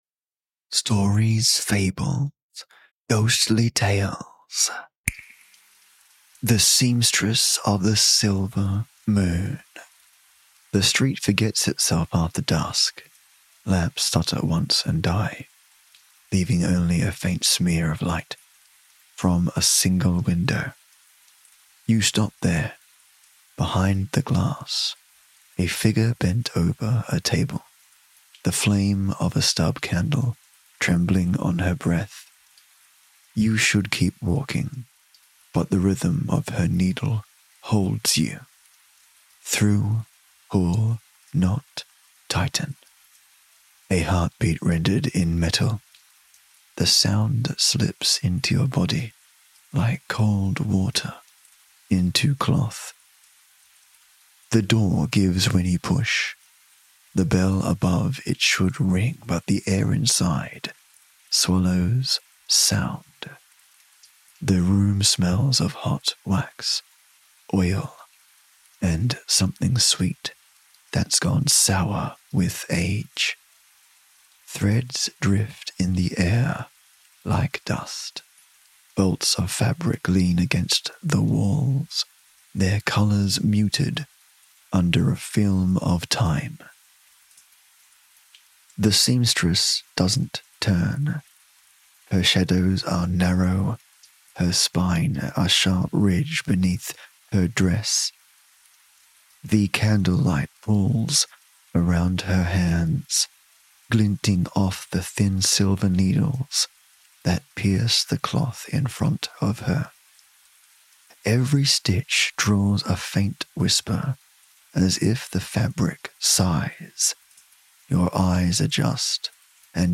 Welcome to The Silver Seamstress , a new gothic horror story for the season — eerie, intimate, and stitched from the very fabric of nightmare.